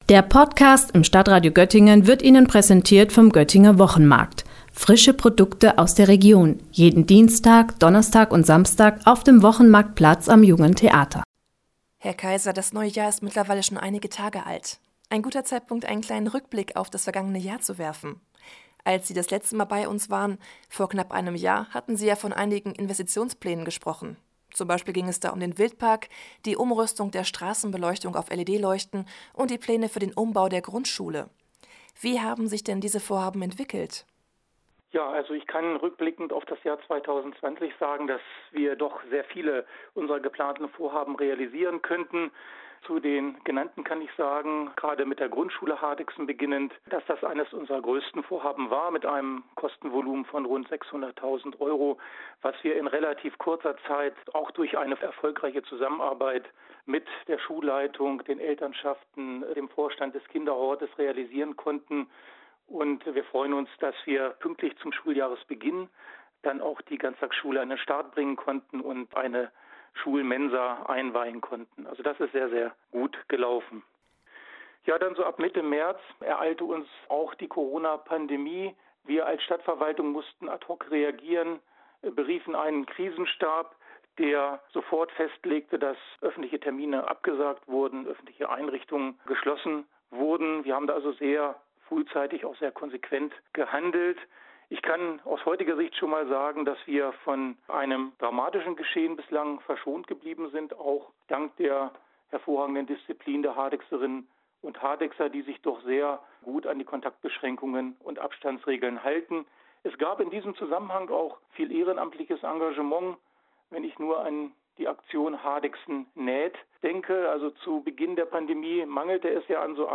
Burgbad, Wildpark, Mehrgenerationenplätze – Hardegser Bürgermeister Michael Kaiser berichtet über aktuelle und zukünftige Projekte